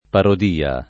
[ parod & a ]